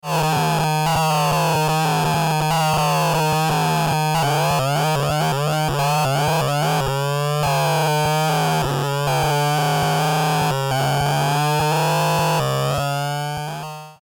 Citation : pfruuuuuuitttt fraaaaat skuiiiiiizzzzz abelelelelele
deeboopdadedo.mp3